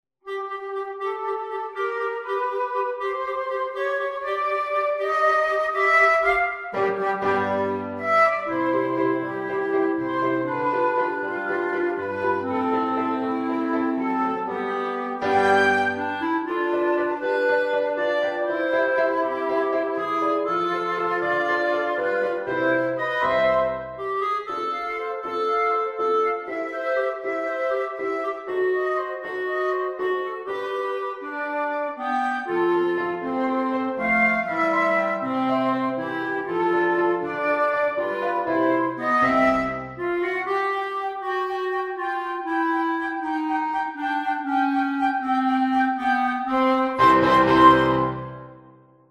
(Flute, Clarinet and Piano accompaniment)